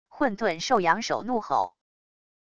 混沌兽仰首怒吼wav音频